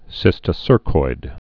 (sĭstĭ-sûrkoid)